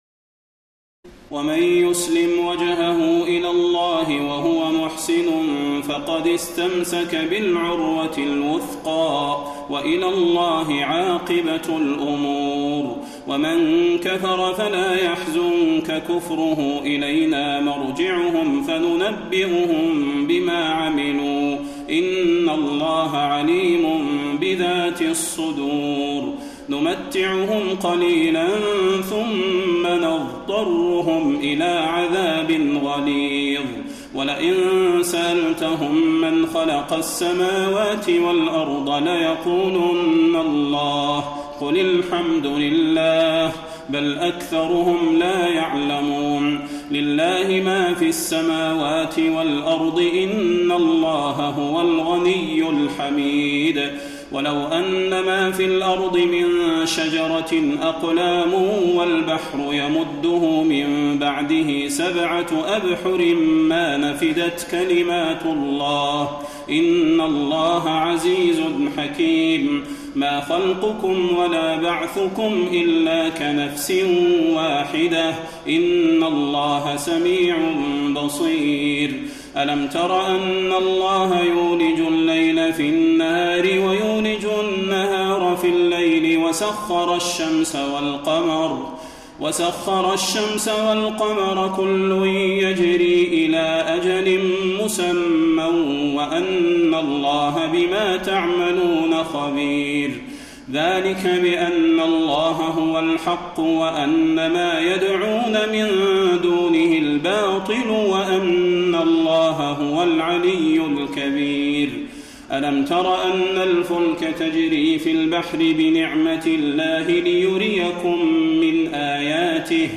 تراويح الليلة العشرون رمضان 1432هـ من سور لقمان (22-34) والسجدة و الأحزاب (1-27) Taraweeh 20 st night Ramadan 1432H from Surah Luqman and As-Sajda and Al-Ahzaab > تراويح الحرم النبوي عام 1432 🕌 > التراويح - تلاوات الحرمين